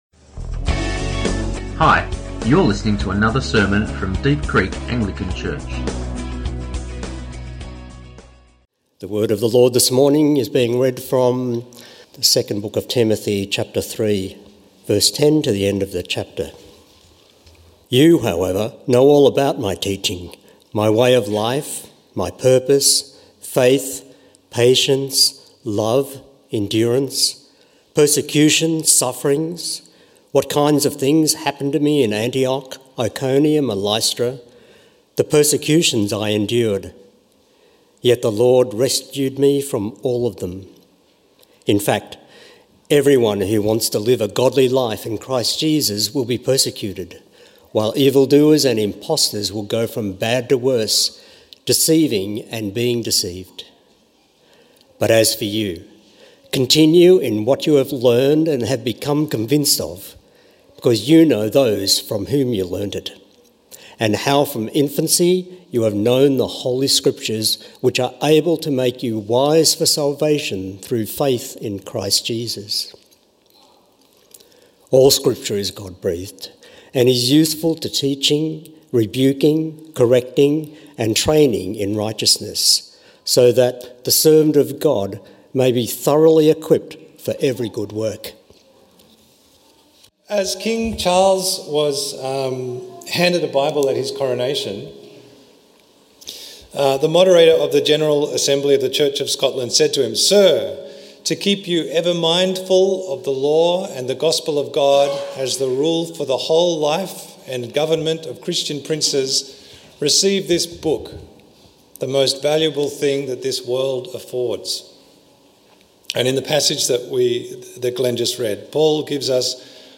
Sermons | Deep Creek Anglican Church